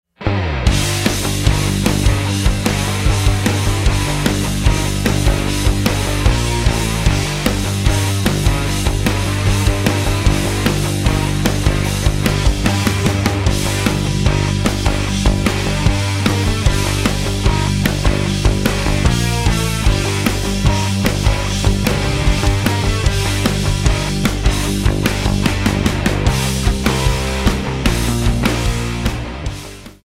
rock instrumental
À forte prédominance de guitares électriques